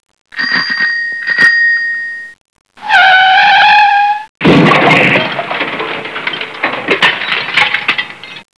cycleaction.wav